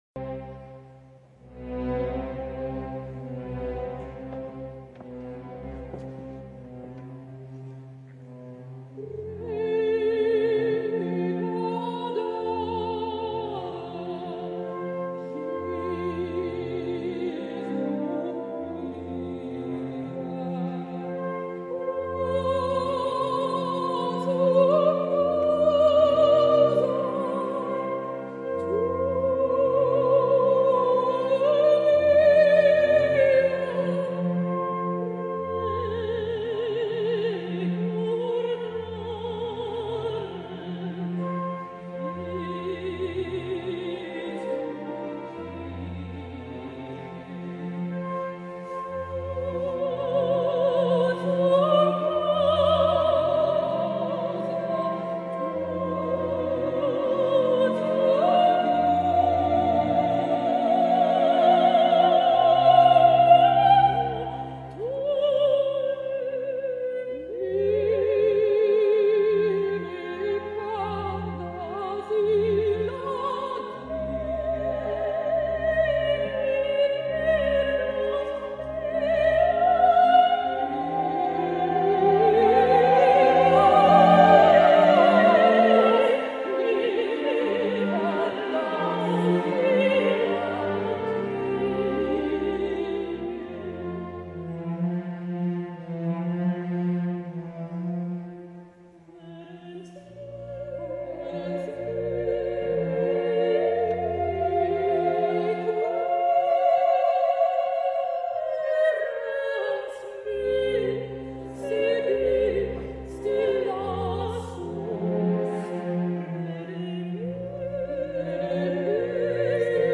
Verdi Requiem Duett Recordare / November 2009